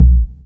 44_30_tom.wav